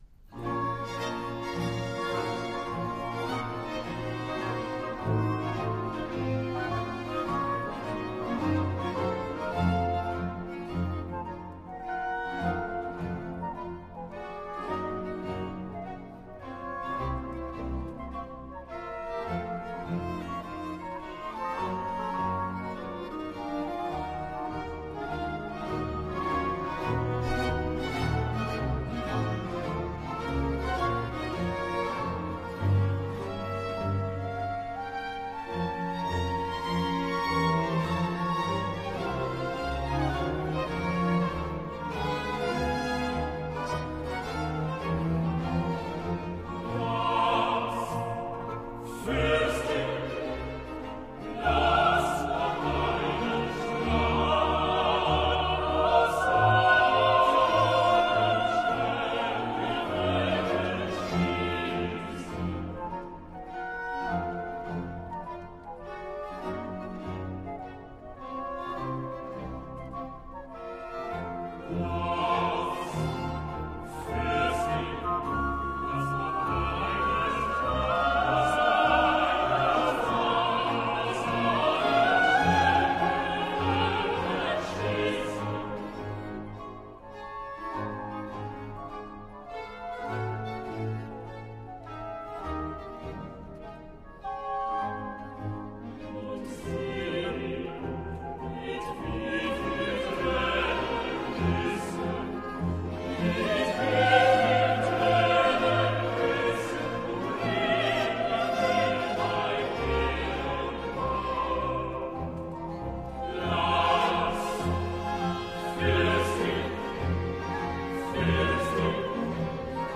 bach - cantate bwv 198 TrauerOde -01 choeur@@.mp3